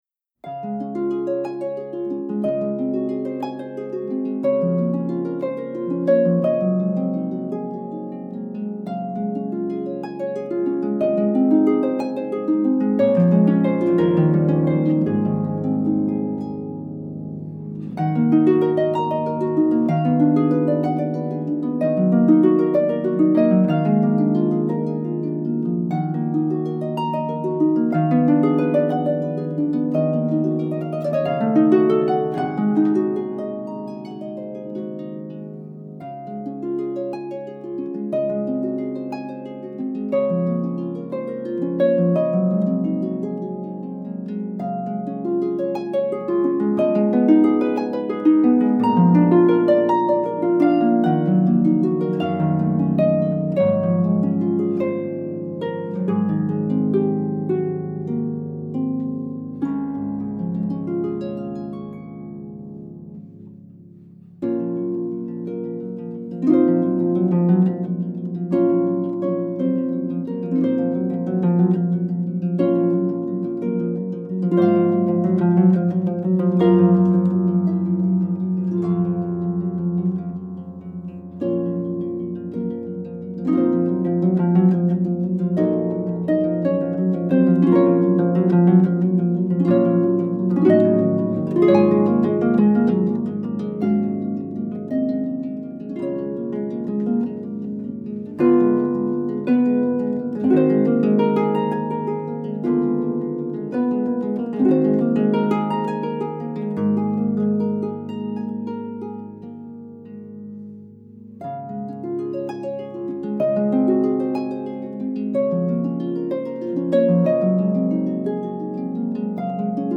Voicing: Harp/CD